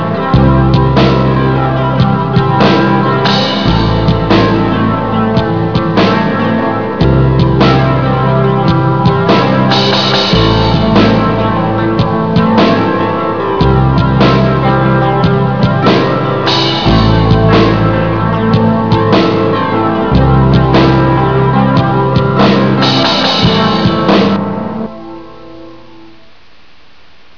experimental, strange,and exotic sound